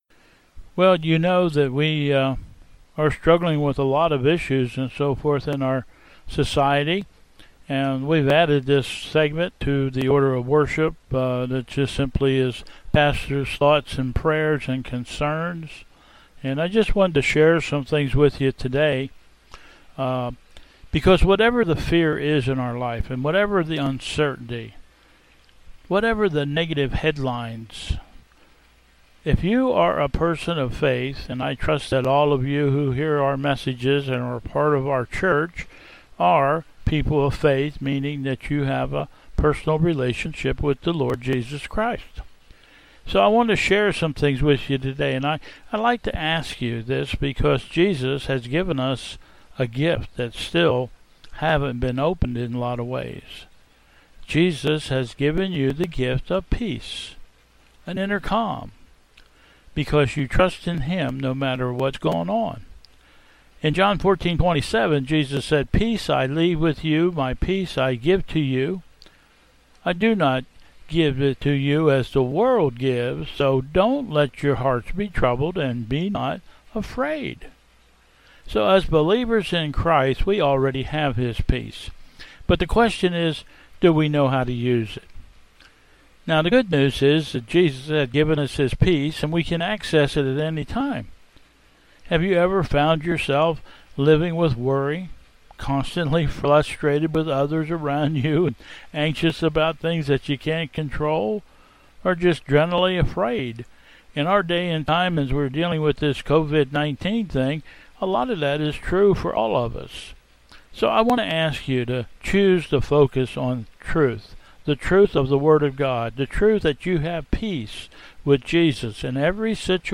Processional
Last Sunday's Service at Bethel 5th Sunday of Easter Mother's Day May 10, 2020